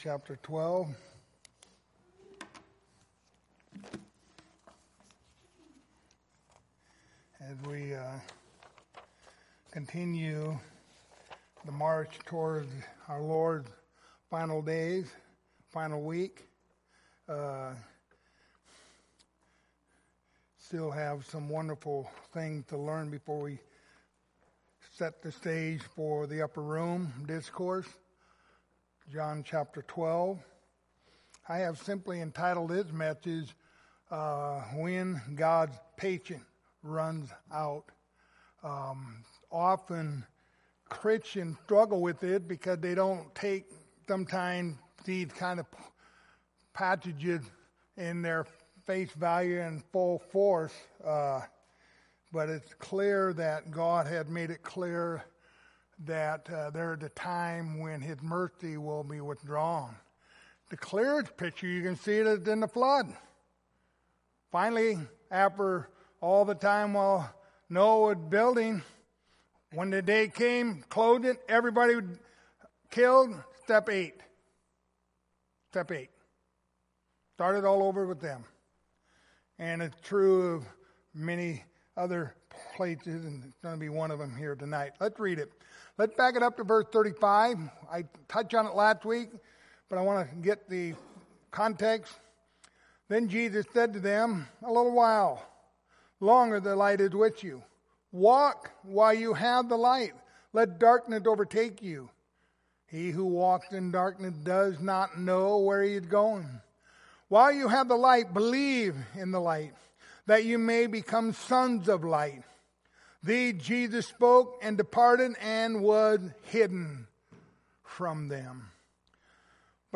Series: The Gospel of John Passage: John 12:35-43 Service Type: Wednesday Evening